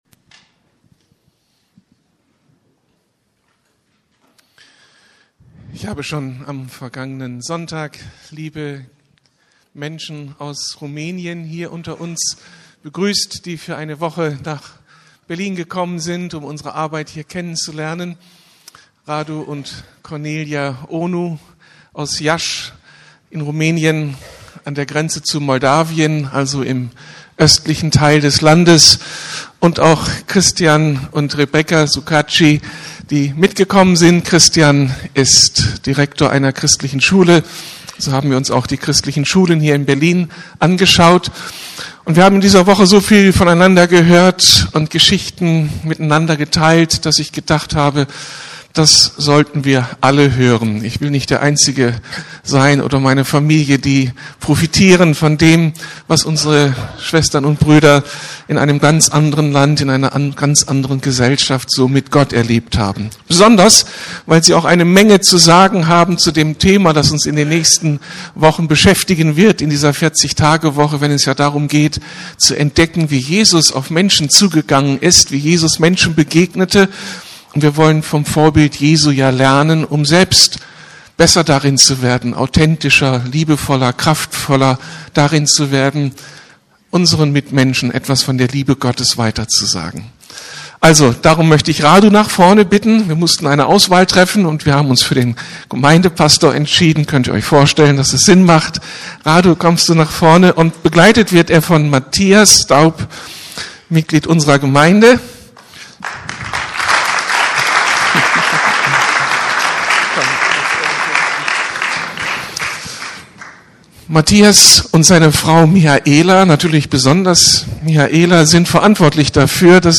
Gott baut sein Reich in Rumänien ~ Predigten der LUKAS GEMEINDE Podcast